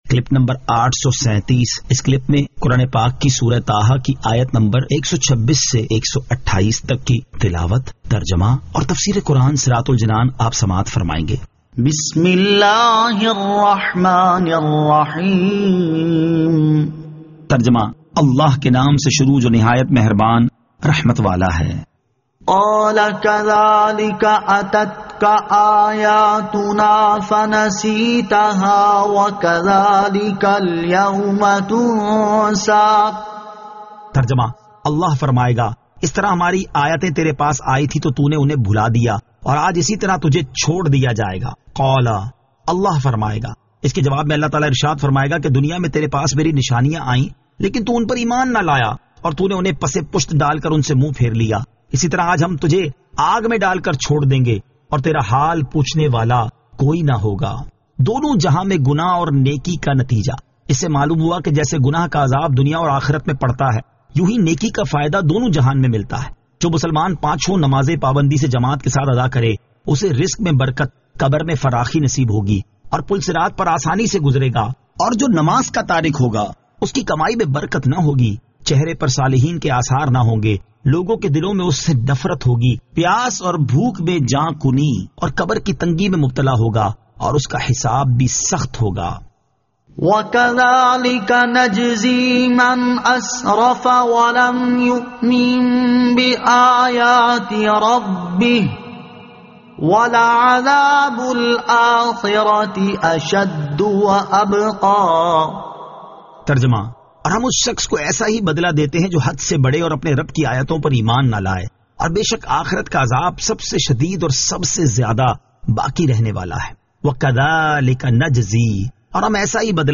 Surah Taha Ayat 126 To 128 Tilawat , Tarjama , Tafseer